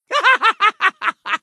laughter_03